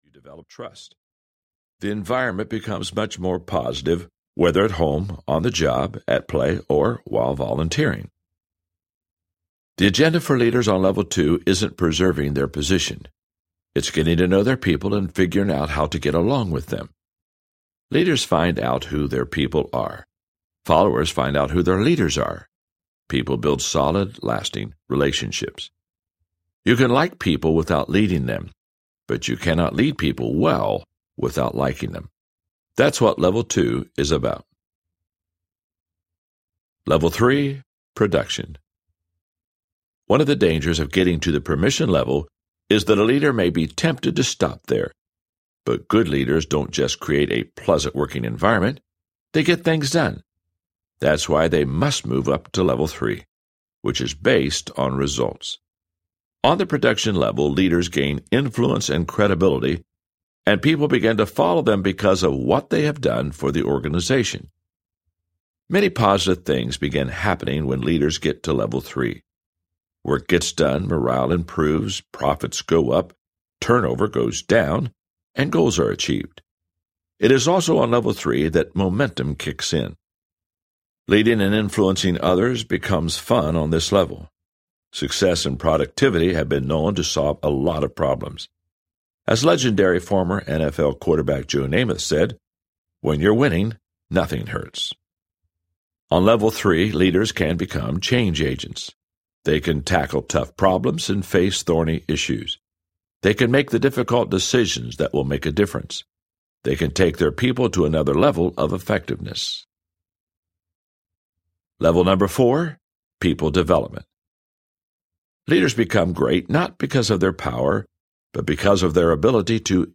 The 5 Levels of Leadership Audiobook
The 5 Levels of Leadership Hachette Audio SAMPLE.mp3